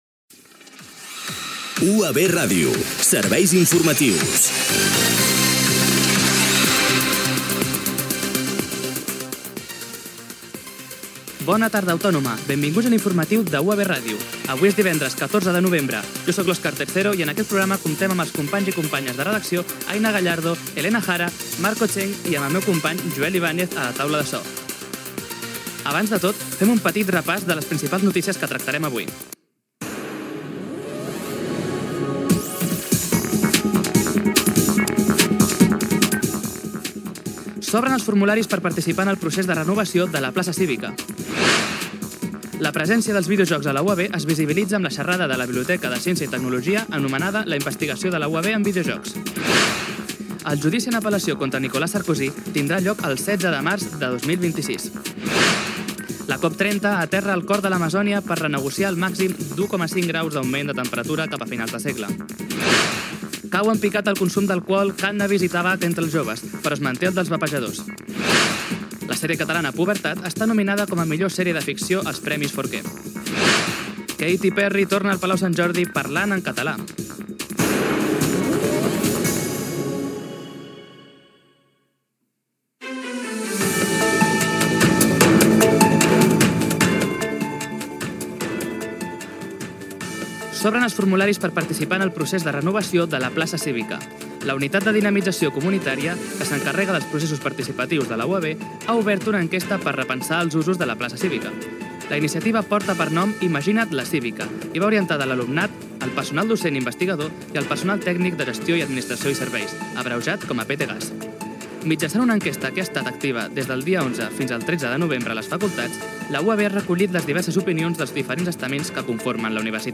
Careta del programa, data, equip, sumari informatiu. La renovació de la Plaça Cívica de la UAB. Els videojocs al món educatiu.
Gènere radiofònic Informatiu